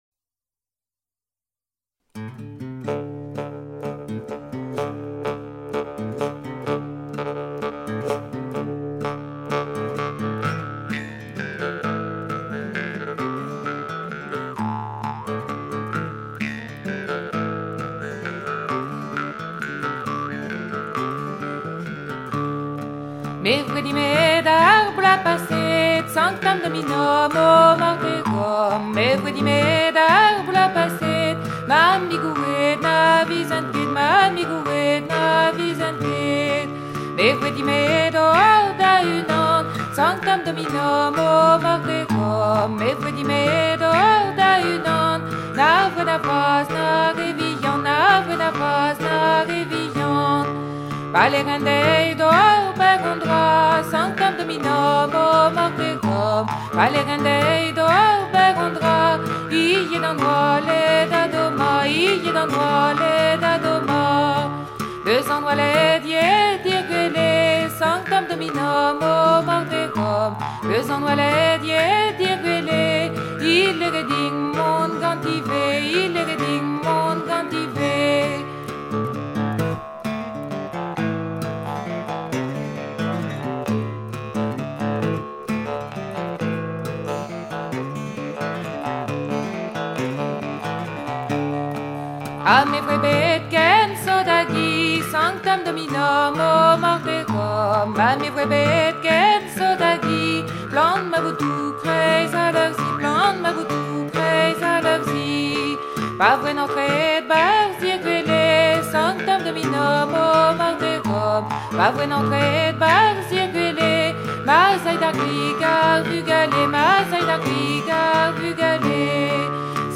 circonstance : maritimes
Genre laisse
Pièce musicale éditée